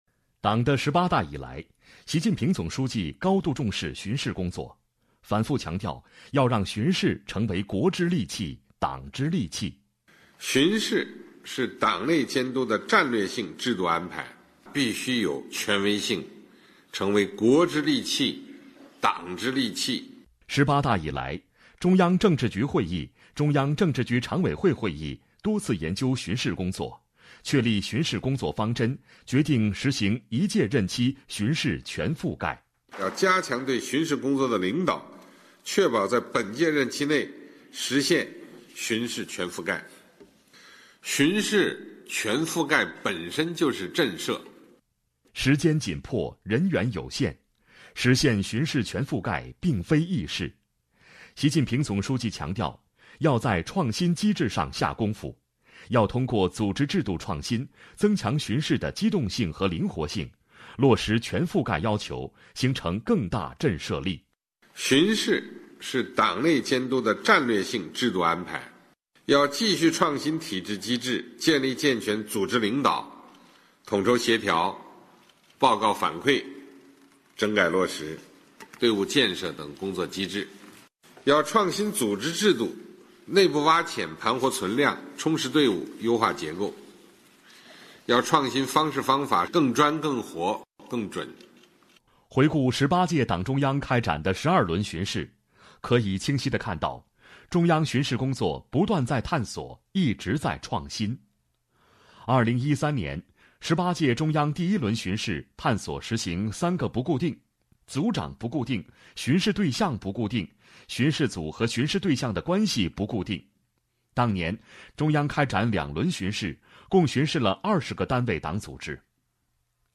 【原声】中央巡视这五年——全覆盖、强震慑、常创新、再启程